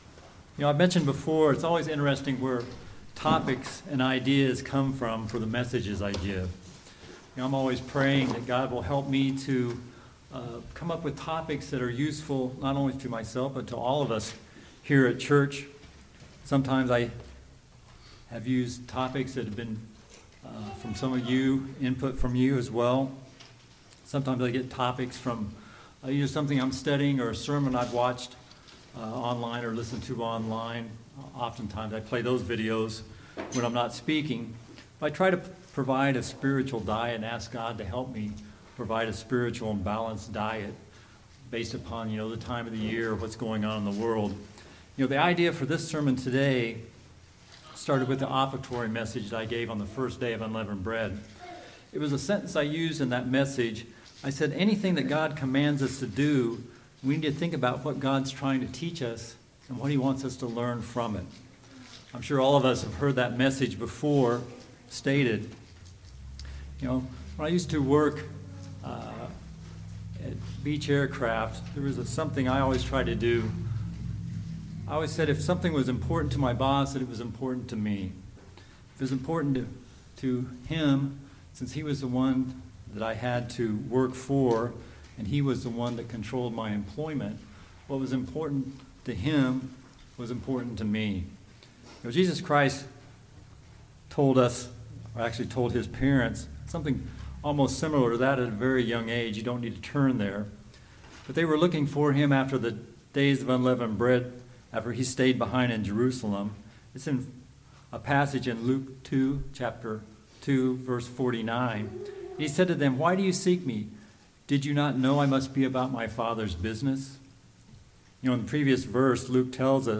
This sermon addresses what the Bible teaches us about tithing and its spiritual importance to each of us. It answers the questions of: Why do we tithe? How do we tithe? Most importantly, what are we to learn?